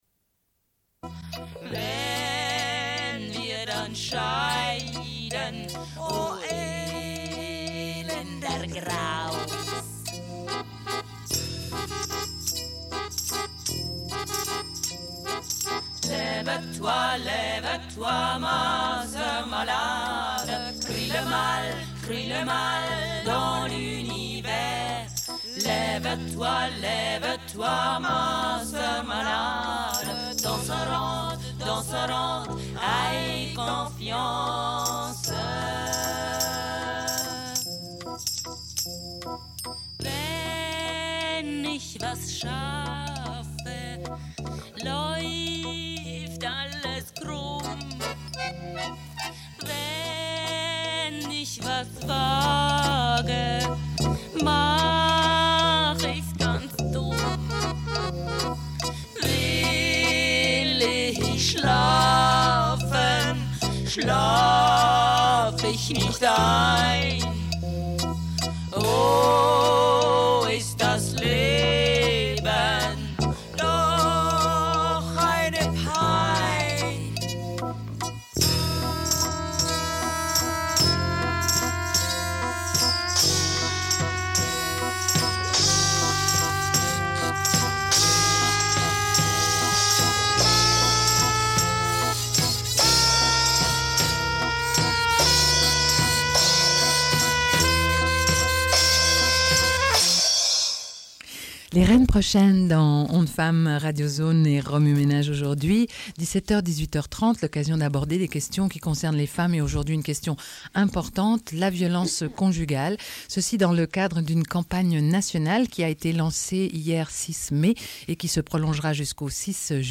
Radio Enregistrement sonore